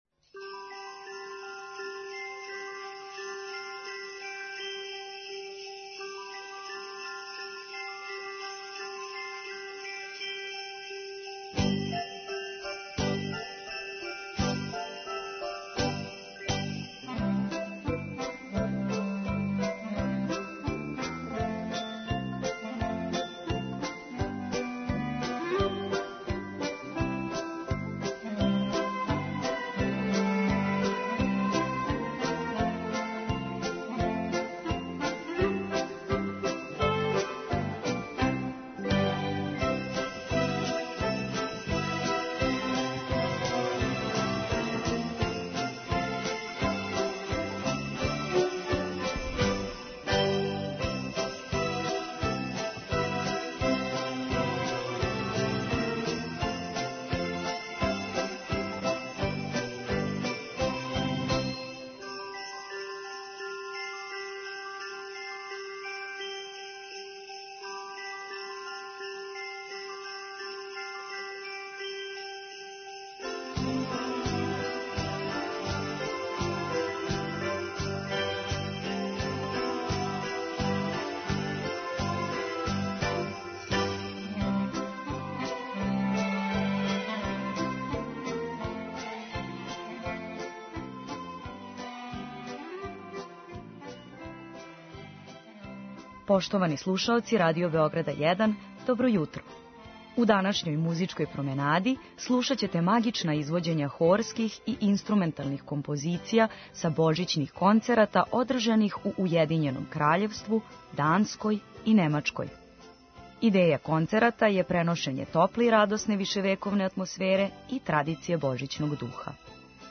Emitujemo magična izvođenja horskih i instrumentalnih kompozicija sa Božićnih koncerata održanih ove godine u Ujedinjenom Kraljevstvu, Danskoj i Nemačkoj.
Ideja koncerata je da prenesu toplu i radosnu viševekovnu atmosferu i tradiciju Božićnog duha. Na repertoaru božićnih koncerata su dela od baroka do kompozicija eminentnih modernih autora, kao i tradicionalne melodije rasprostranjene širom sveta.